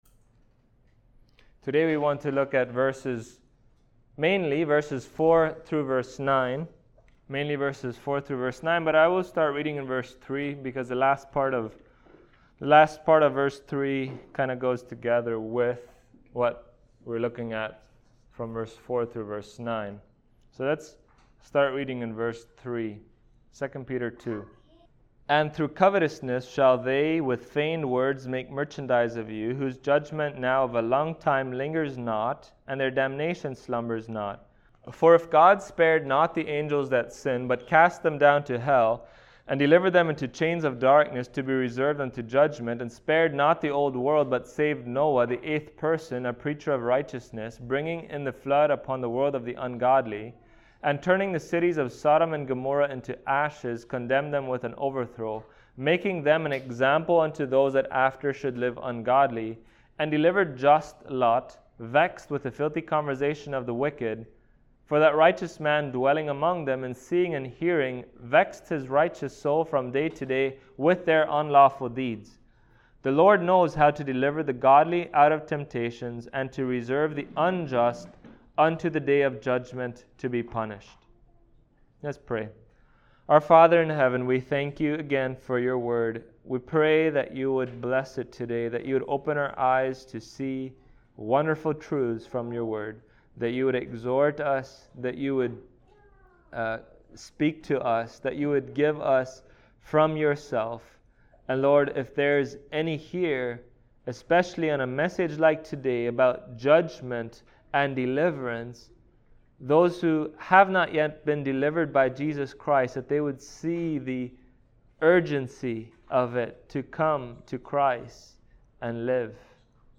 2 Peter Passage: 2 Peter 2:4-9 Service Type: Sunday Morning Topics